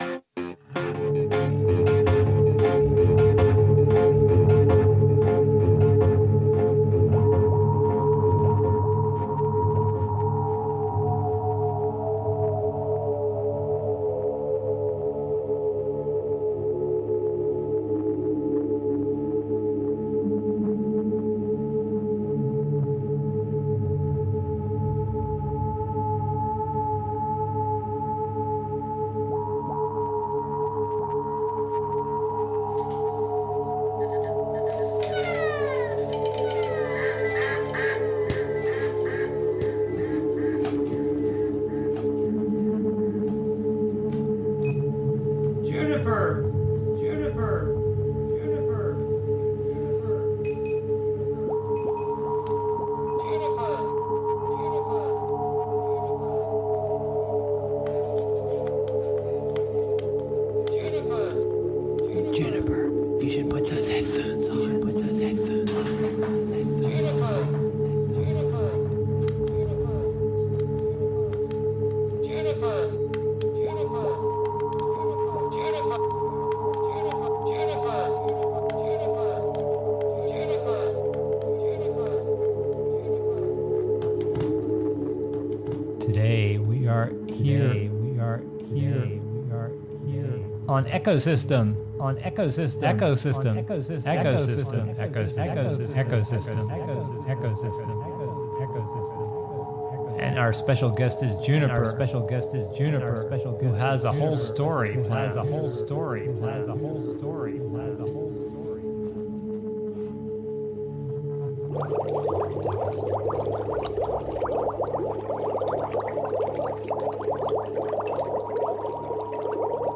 EchoSystem is a weekly youth program where friends come together to talk, learn, and experiment with sound, music, and radio.